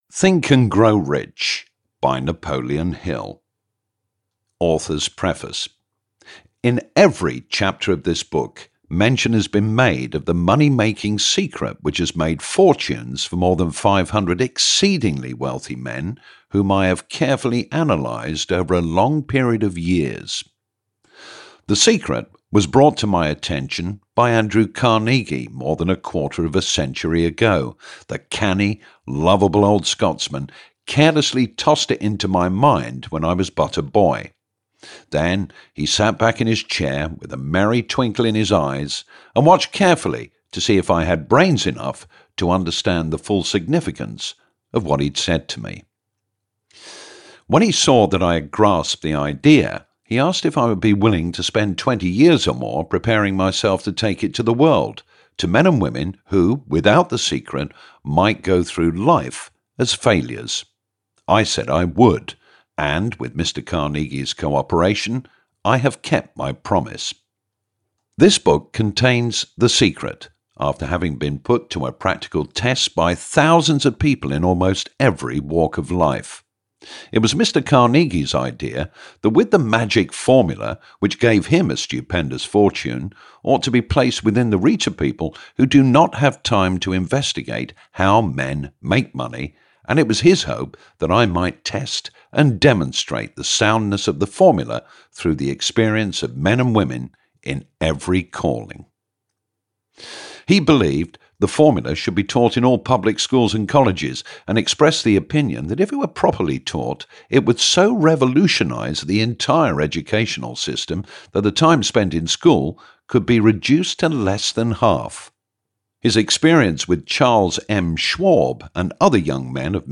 Think and Grow Rich (EN) audiokniha
Ukázka z knihy